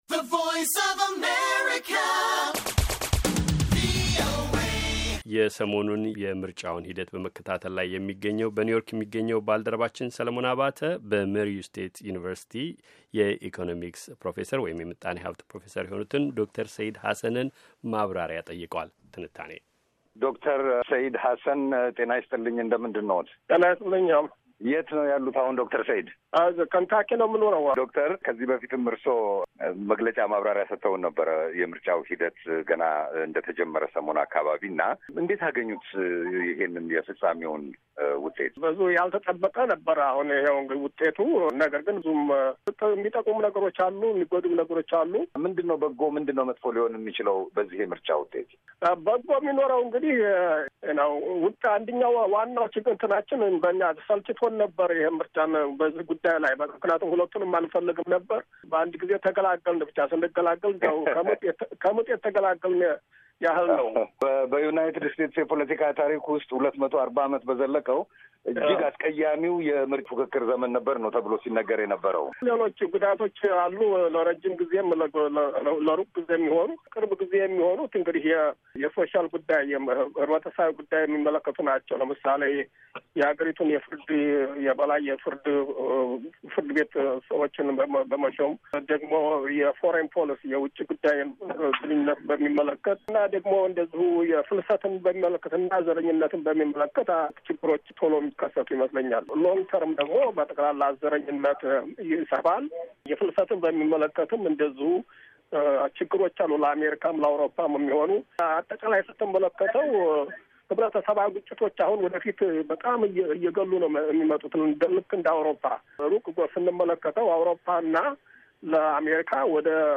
የተደረገ ቃለ ምልልስ